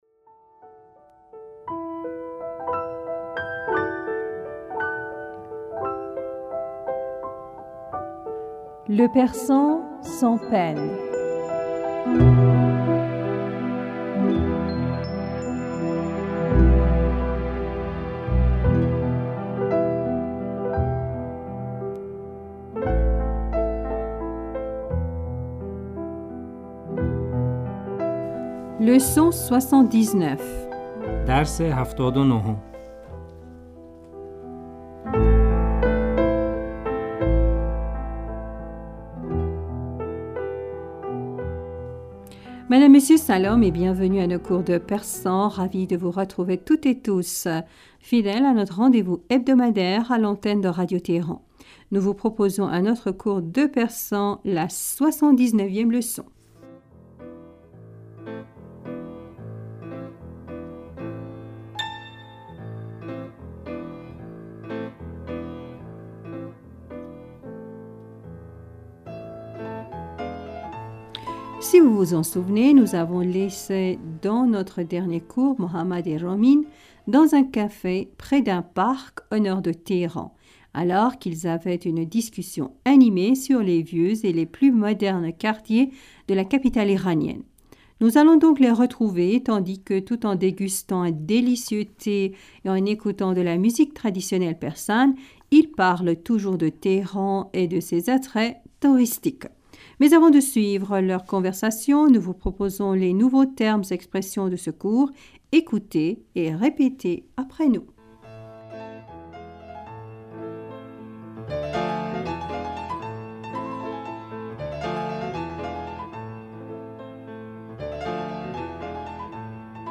Madame, Monsieur Salam et bienvenus à nos cours de persan.
Ecoutez et répétez après nous.